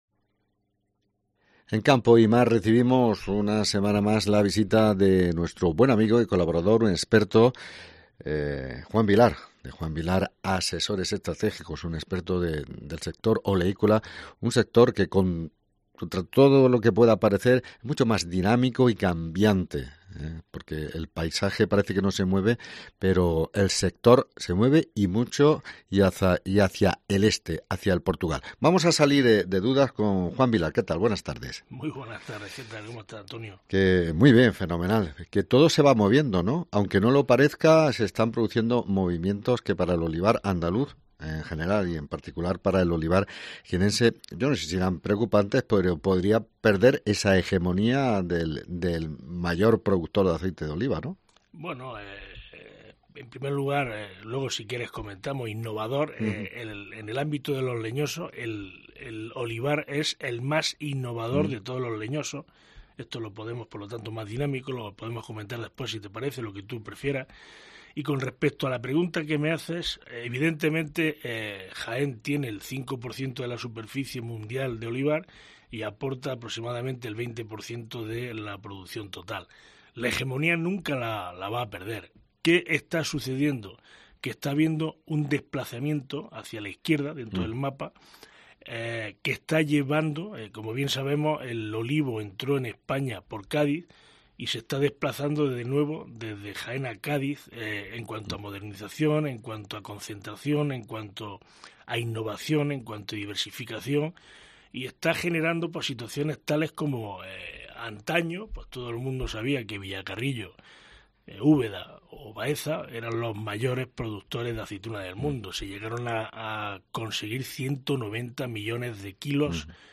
Jaén - Huelma ANÁLISIS ¿Qué está pasando en el olivar?